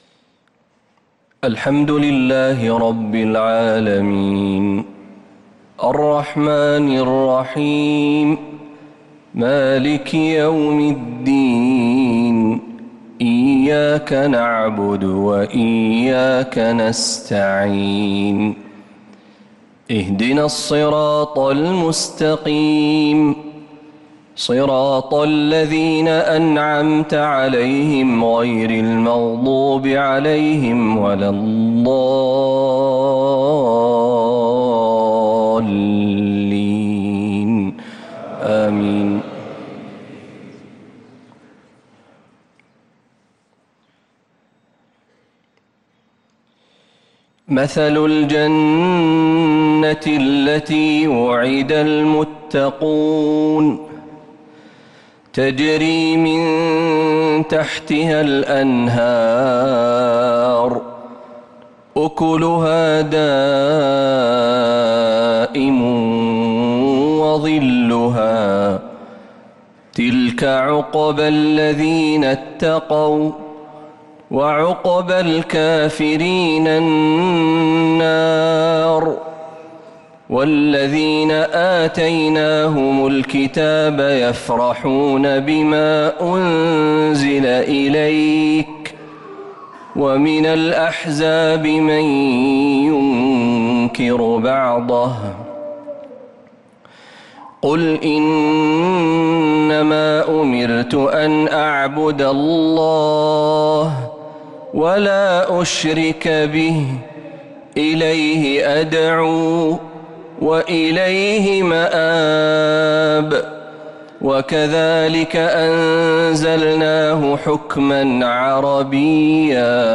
مغرب الثلاثاء 13 محرم 1447هـ | خواتيم سورة الرعد 35-43 | Maghrib prayer from surat al-raed 8-7-2025 > 1447 🕌 > الفروض - تلاوات الحرمين